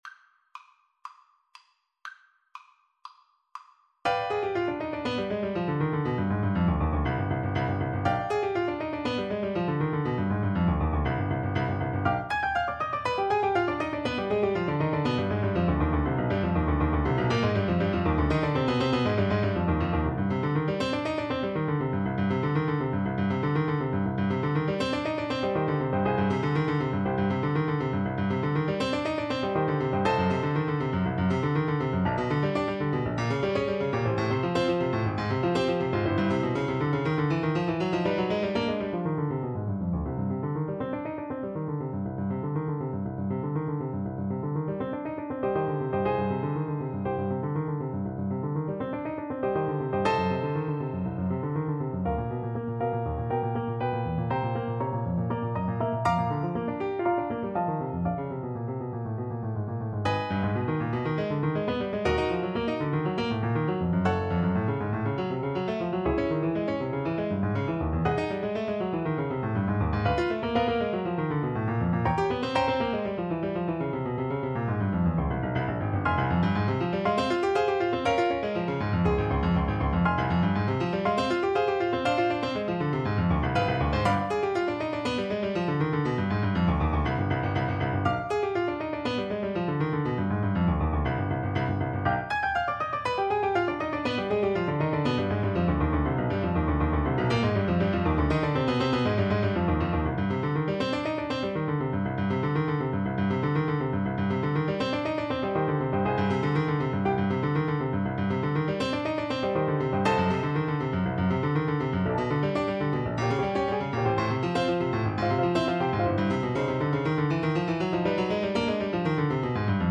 Play (or use space bar on your keyboard) Pause Music Playalong - Piano Accompaniment Playalong Band Accompaniment not yet available transpose reset tempo print settings full screen
C minor (Sounding Pitch) (View more C minor Music for Cello )
Allegro con fuoco (View more music marked Allegro)
4/4 (View more 4/4 Music)
Classical (View more Classical Cello Music)